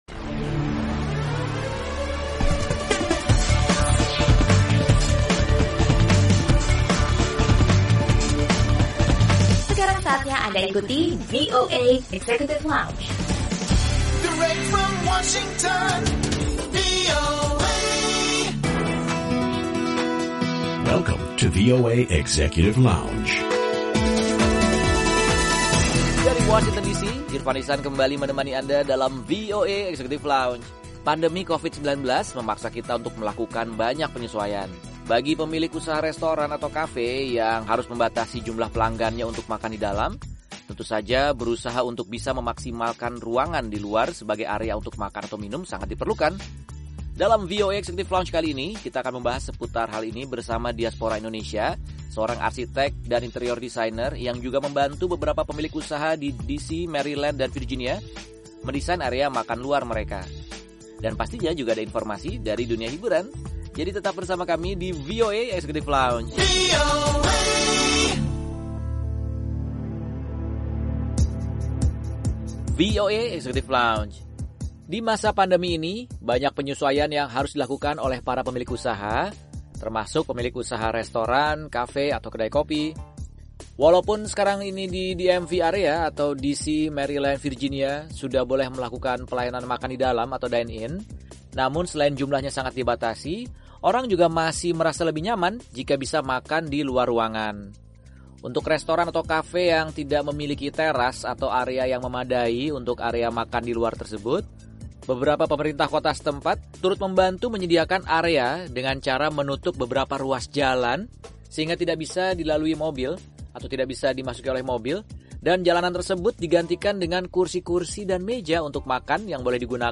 Obrolan bersama diaspora Indonesia yang berprofesi sebagai seorang arsitek dan interior designer